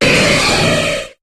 Cri de Cizayox dans Pokémon HOME.